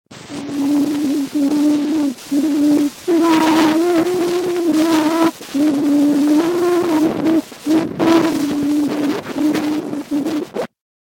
En el año 2008, fue posible realizar esta conversión y se reprodujo el que se conoce como el primer sonido registrado, "Au Clair de La Lune", canción popular francesa, confirmando que el fonoautógrafo había hecho la primera grabación de sonido conocida.